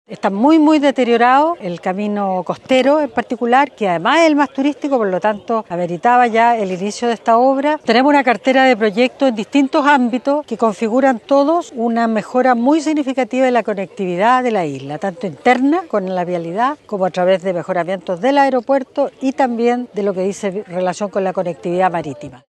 Hasta la isla llegó la ministra de Obras Públicas, Jessica López, quien se refirió a la inversión directa del MOP, la que supera los $14 mil millones.
cu-inicio-pavimentacion-rapa-nui-ministra.mp3